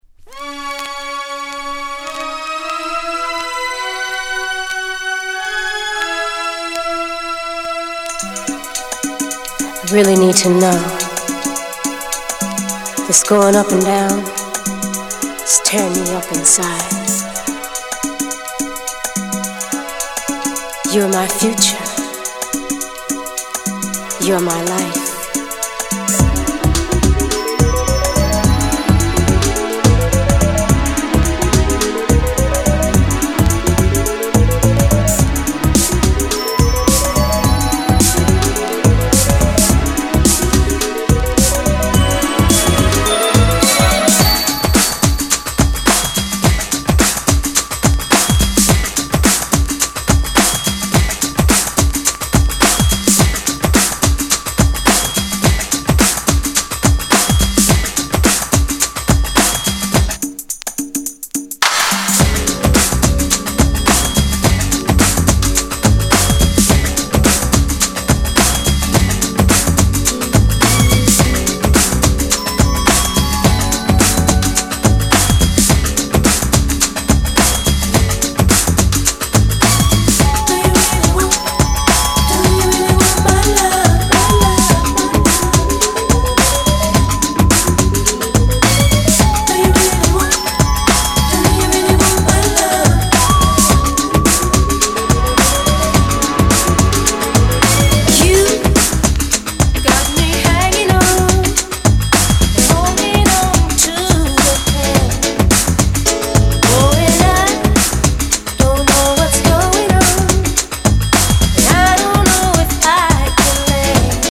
Genre:  Soul